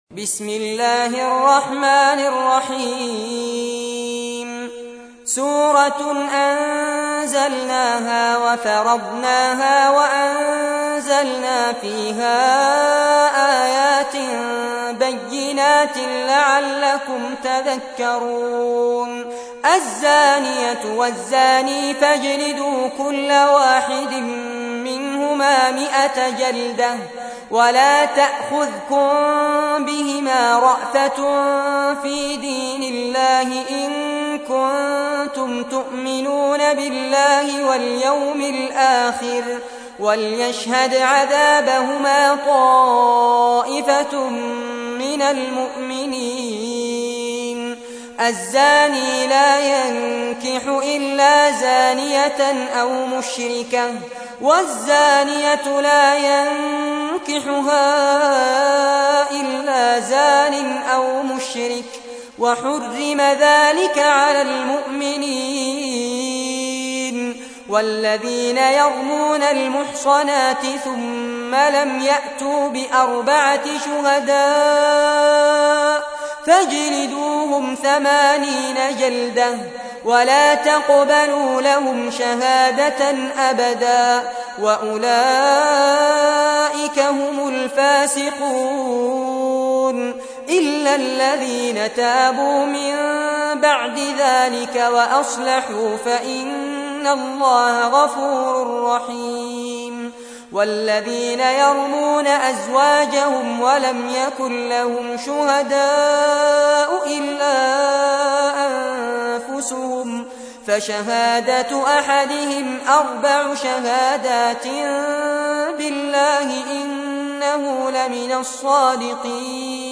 تحميل : 24. سورة النور / القارئ فارس عباد / القرآن الكريم / موقع يا حسين